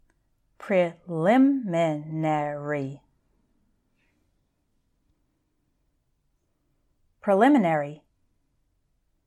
I’ll say each word twice – once a little more slowly with some separation between the syllables, and then once at normal speed, and I’d like you to repeat after me both times.
spon-tan-E-i-ty: spontaneity
con-TIN-u-ous-ly: continuously
ex-PLAN-a-to-ry: explanatory
tech-no-LOG-i-cal: technological